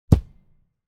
دانلود آهنگ تصادف 55 از افکت صوتی حمل و نقل
دانلود صدای تصادف 55 از ساعد نیوز با لینک مستقیم و کیفیت بالا
جلوه های صوتی